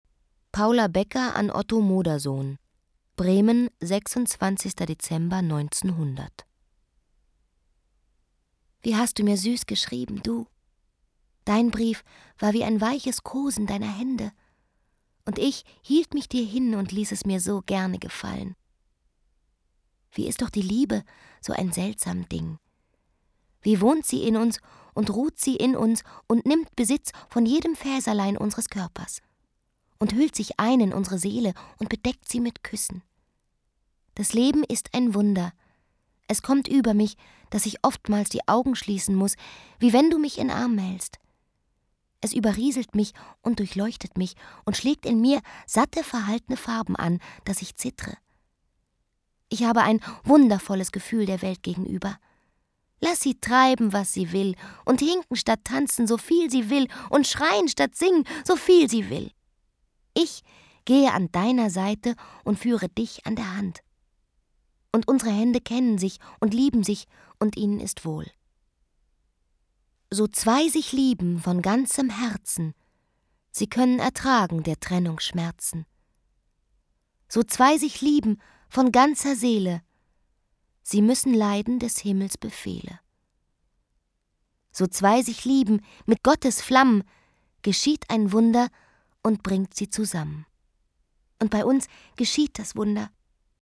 Anna Thalbach (Sprecher)